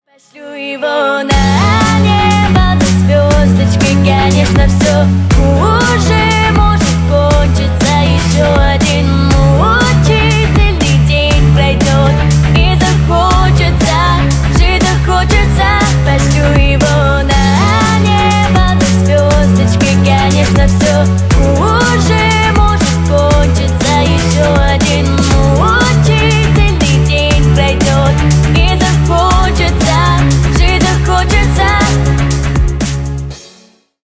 Поп Музыка
кавер